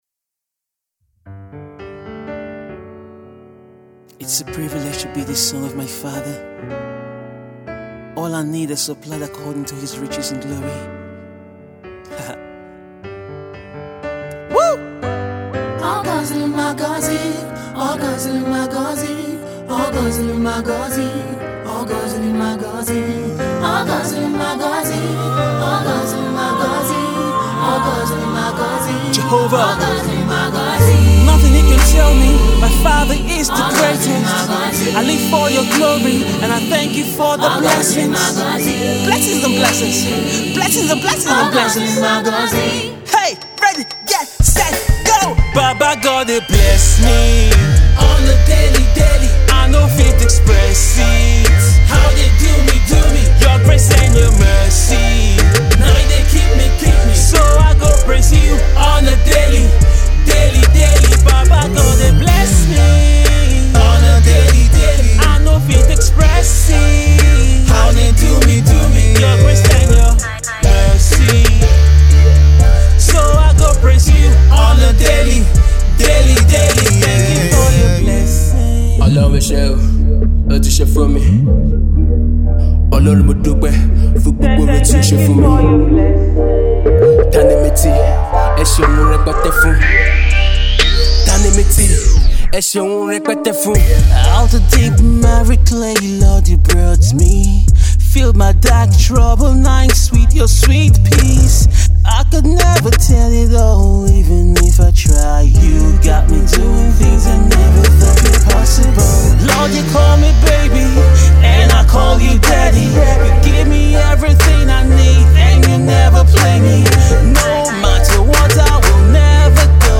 American Christian Hip-Hop artist
amazing Christian Trap tune